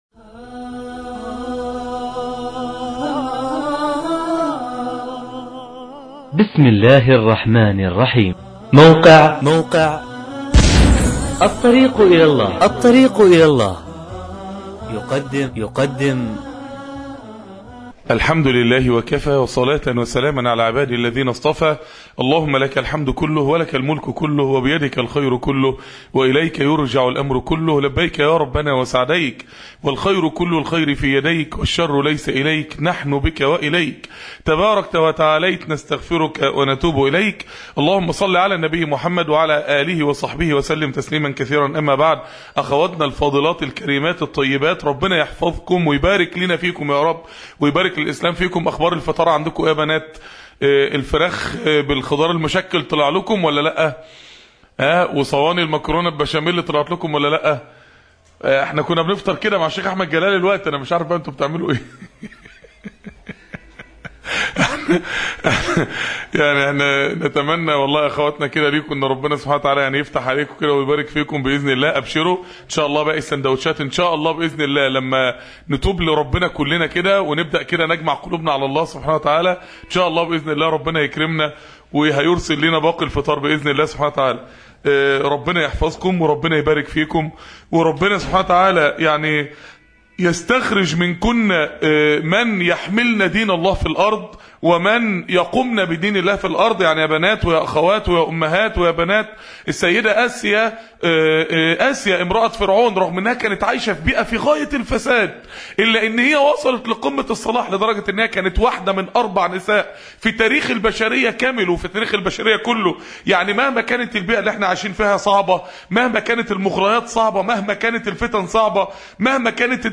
( اليوم التربوي للأخوات بمسجد الصديق بالمنصورة ) كلمة